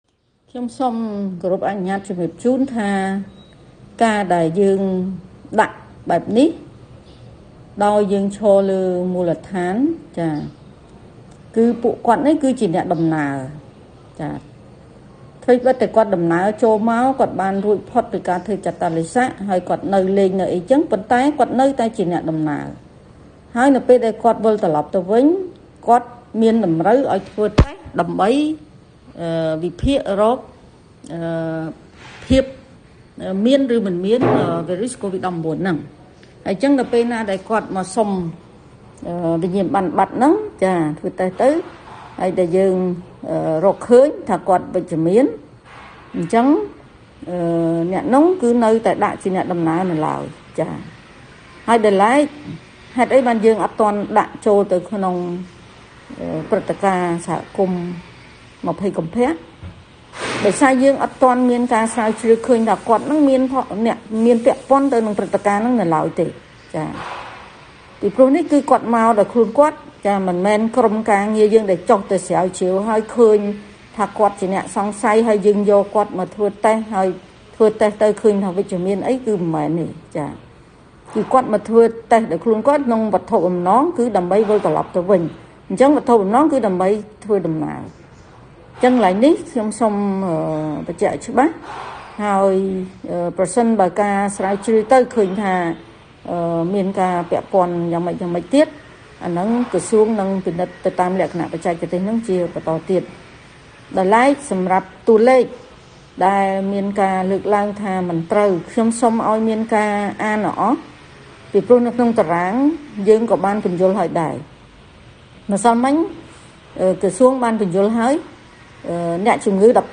តាមរយៈសារសំឡេង នៅថ្ងៃទី២៥ កុម្ភៈនេះ លោកស្រី ឱ វណ្ណឌីន បានរៀបរាប់ថា ក្រសួងមិនចាត់ទុកជនជាតិចិន ៦នាក់ រកឃើញវិរុសកូវីដ-១៩ នៅក្រុងព្រះសីហនុ ចូលទៅក្នុងព្រឹត្តិការណ៍សហគមន៍ ២០កុម្ភៈ ឡើយ។